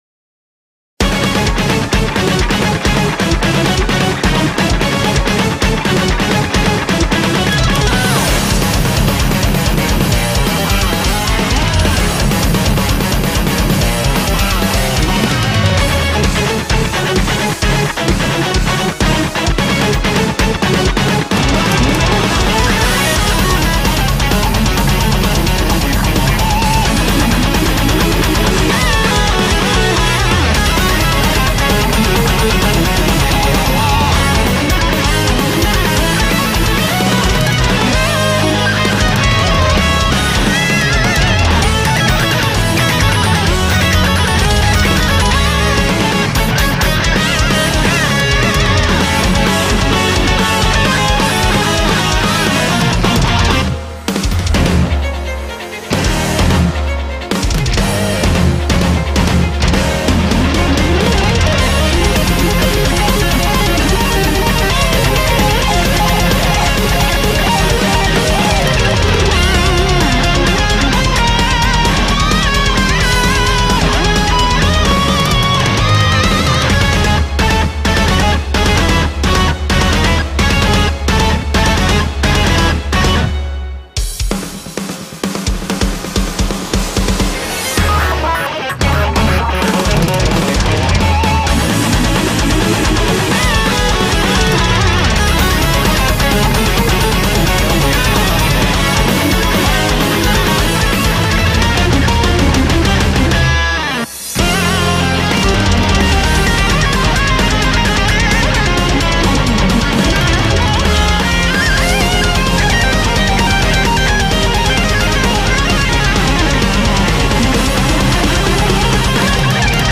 BPM260
Audio QualityPerfect (High Quality)
コメント[METAL]